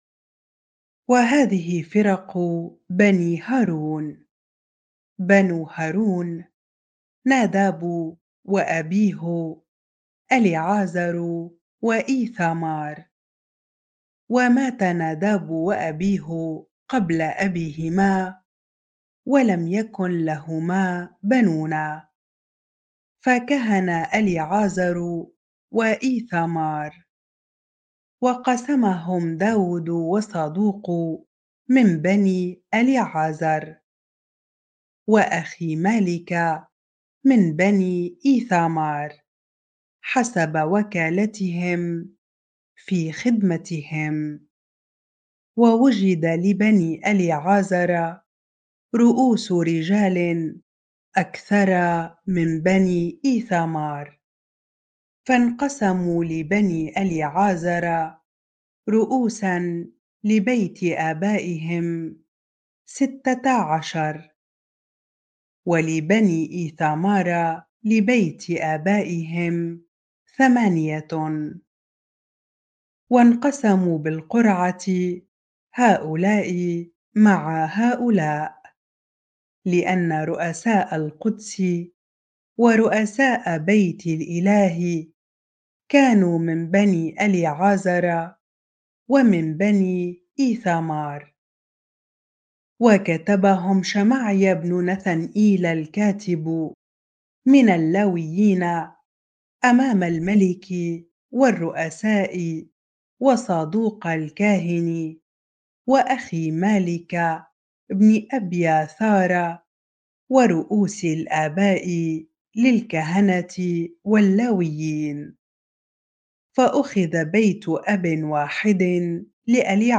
bible-reading-1 Chronicles 24 ar